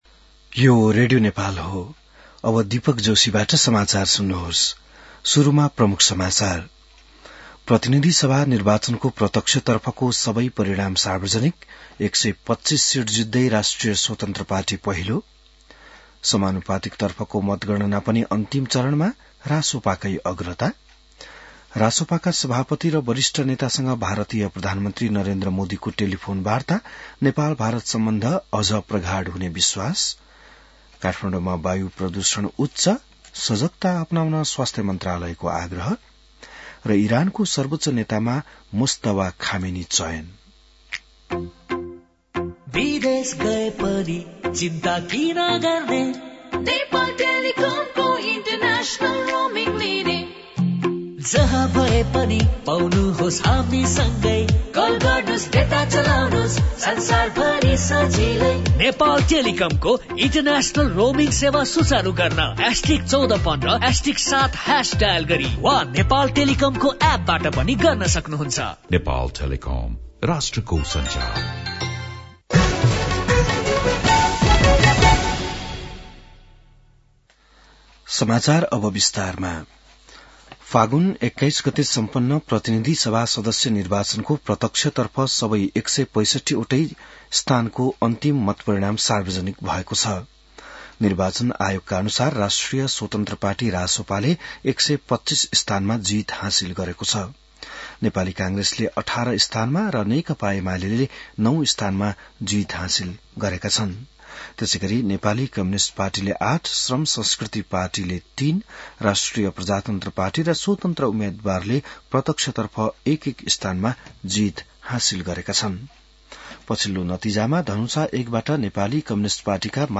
बिहान ७ बजेको नेपाली समाचार : २६ फागुन , २०८२